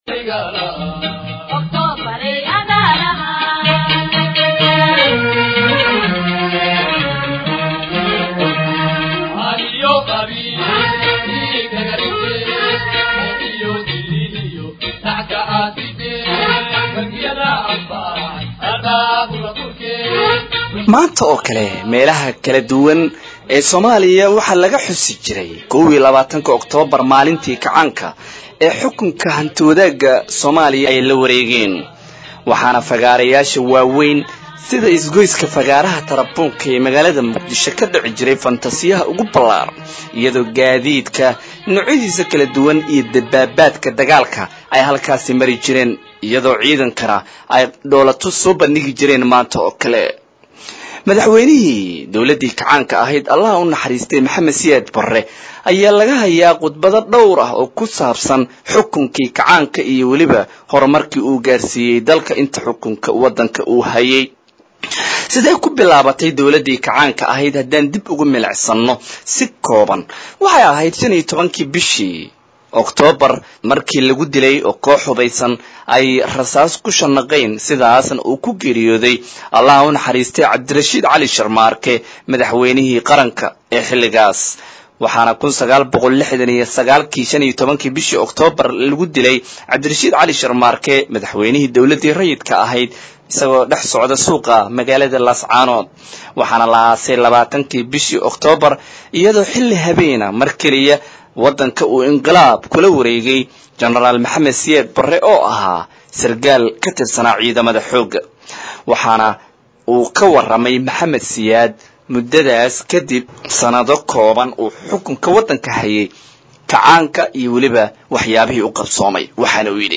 Dib u jaleec khudbad qiimo leh oo uu jeediyay AUN madaxweyihii u dambeeyay dalka: MAQAL